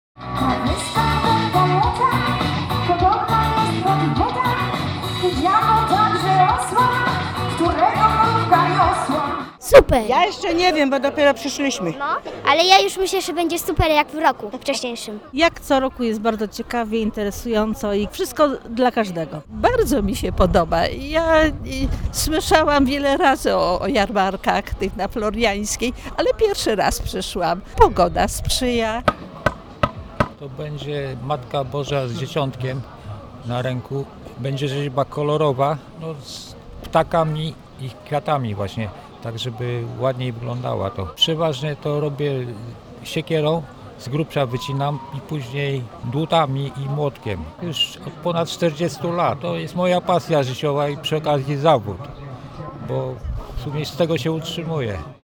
obrazek-jarmark.mp3